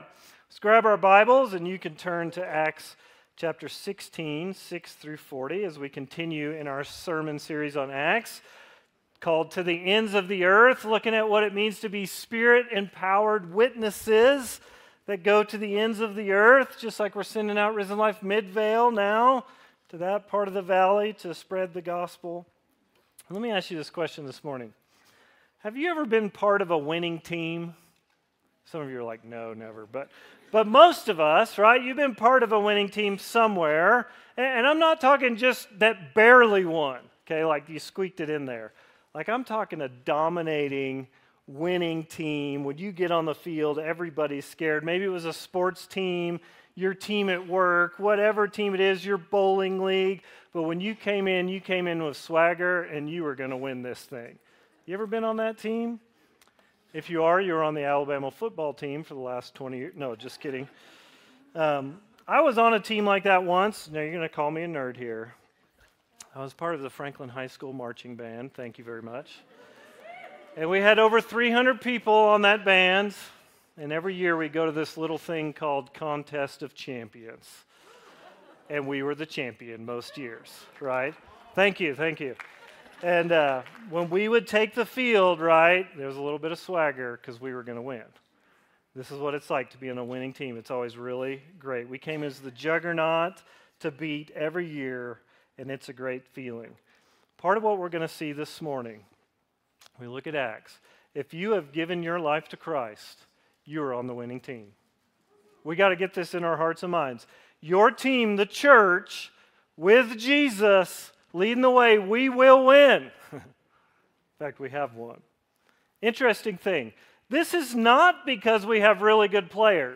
Worship Listen Sermon We will continue in our study of Acts looking at Acts 16:6-40.